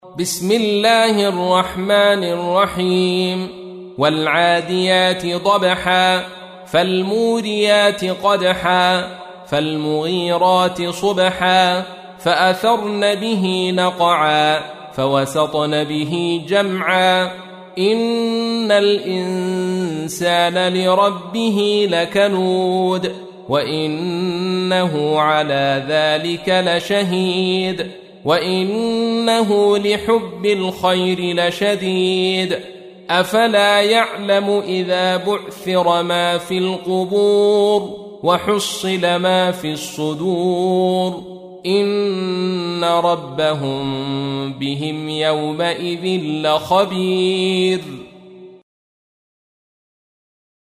تحميل : 100. سورة العاديات / القارئ عبد الرشيد صوفي / القرآن الكريم / موقع يا حسين